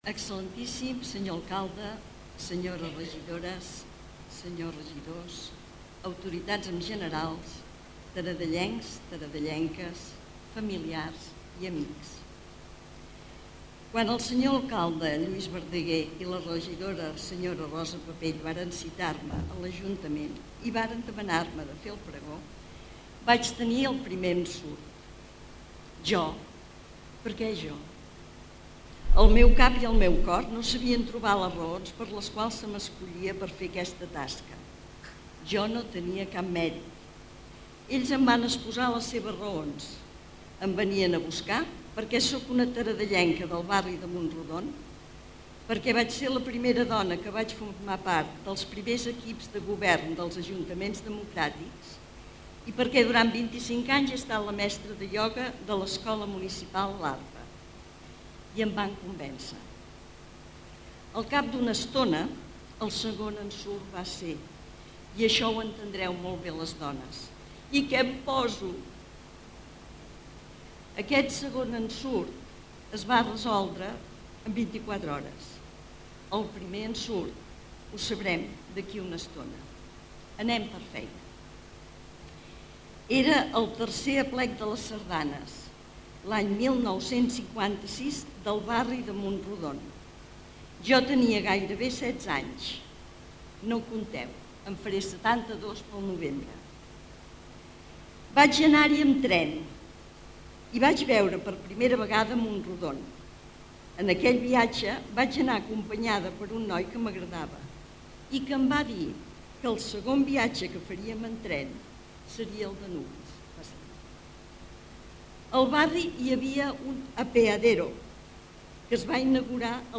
El pregó s'ha convertit en un acte habitual a la festa major de Taradell des de fa molts anys. A més, és l'acte que dóna el tret de sortida, de forma oficial, als següents dies de gresca i activitats per a tots els públics.
Fins el 1998 els pregons es van fer a La Carpa i des del 1999 es fan a la sala gran del Centre Cultural Costa i Font.